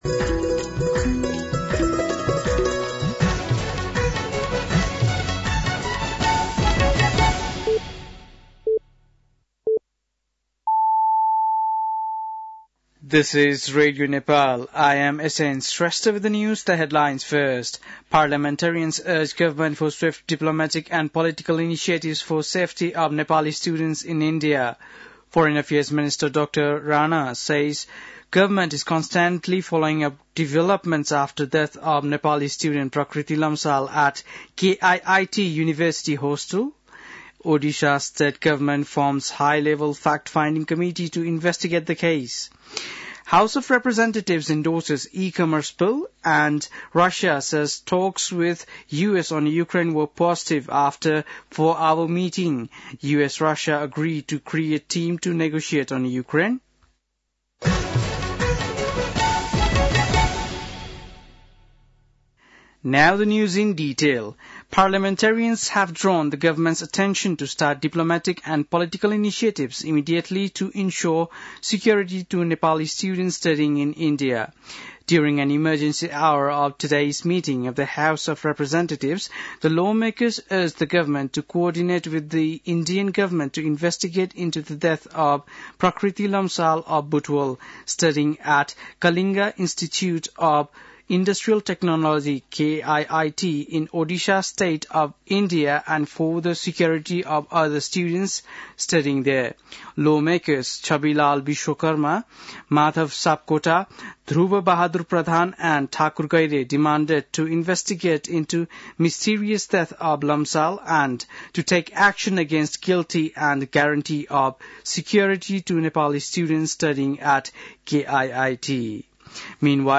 बेलुकी ८ बजेको अङ्ग्रेजी समाचार : ७ फागुन , २०८१